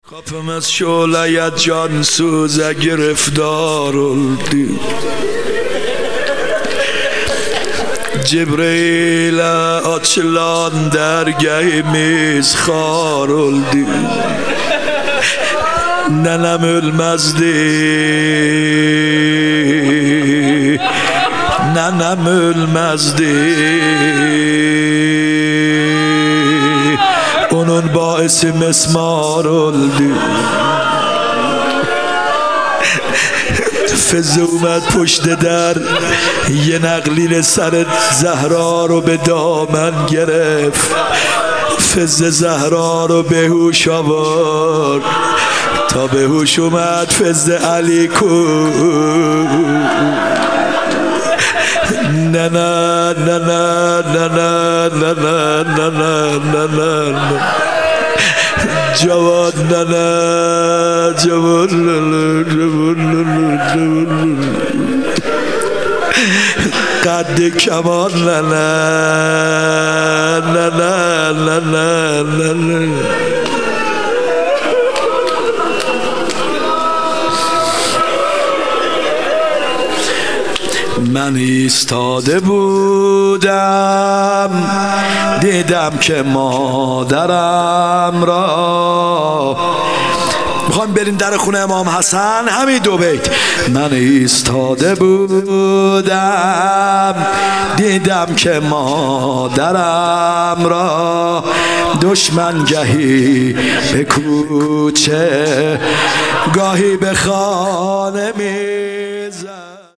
21 رمضان 97 روضه